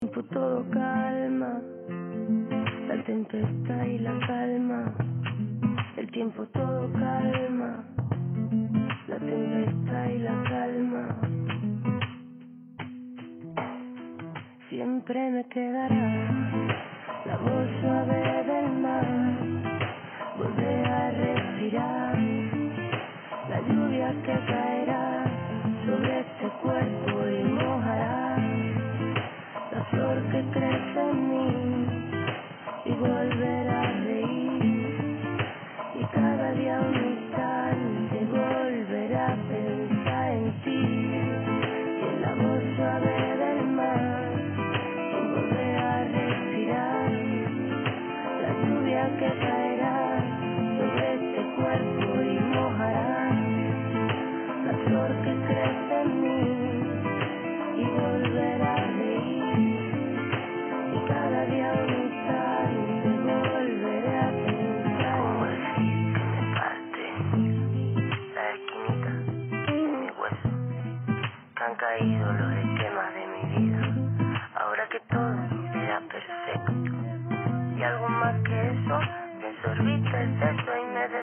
Programma prasa nelielu laiku datu apstrādei un tad datora skaņas kartes izejā parādās kolosāli tīrs audio signāls.
Kāds aptuveni izklausās dekodētais audio signāls var spriest pēc šī mp3 faila atvēršanas: